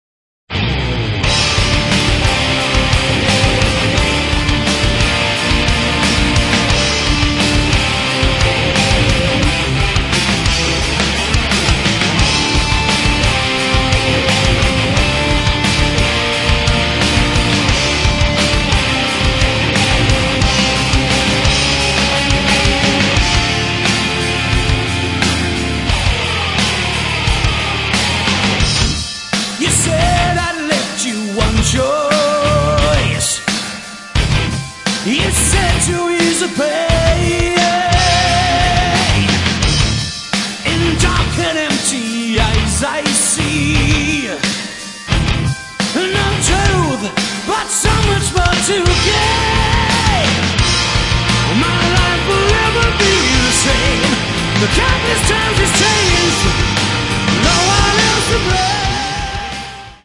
Metal
вокал
гитара
бас, бэк-вокал
барабаны, клавиши, бэк-вокал